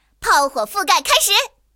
野牛开火语音1.OGG